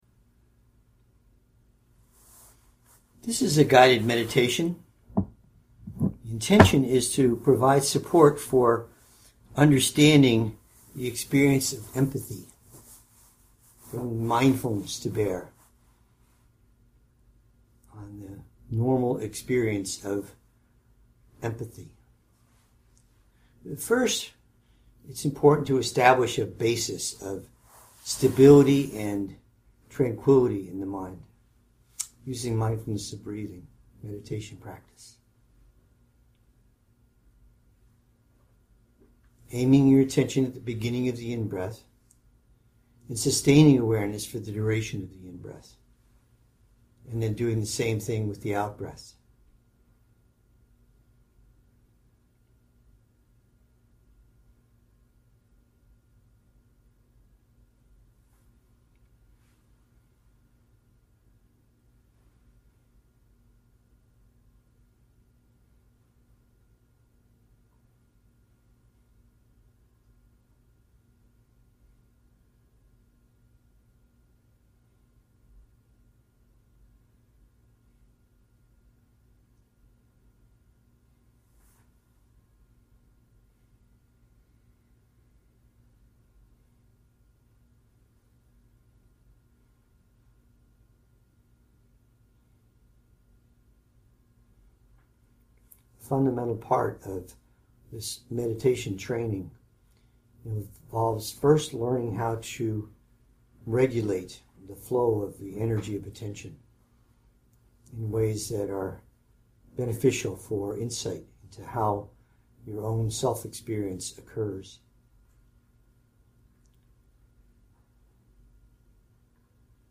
Guided-Empathy-Contemplation.mp3